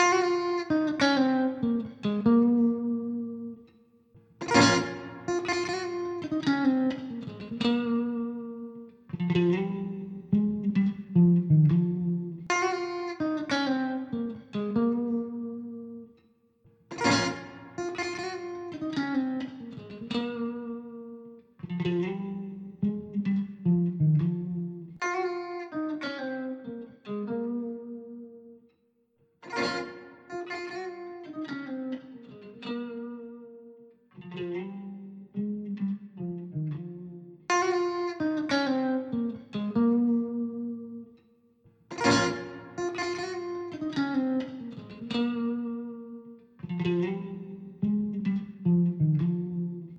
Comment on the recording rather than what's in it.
I also artificially produced a sound test of what would happen if you had one microphone in your system which was wired wrong or broken such that it gave an out-of-phase sound.